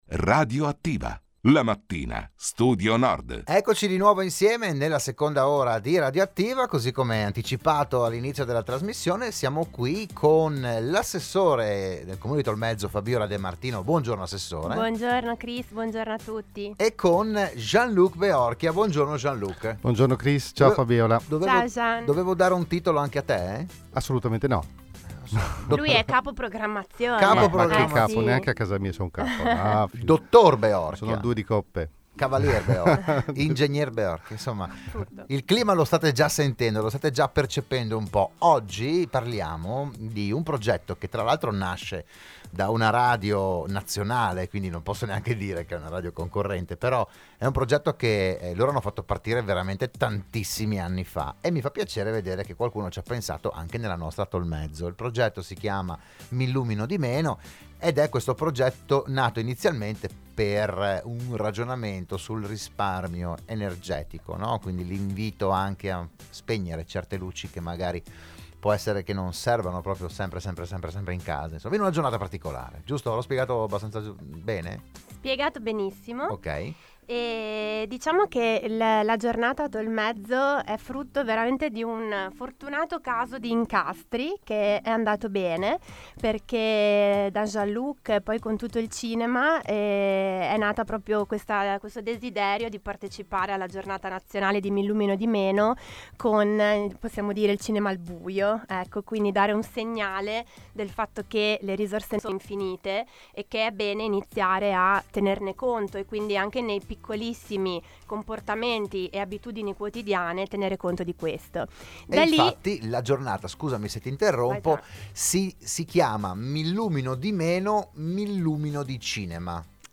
Il PODCAST e il VIDEO dell'intervento a Radio Studio Nord